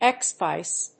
シー‐ピーシー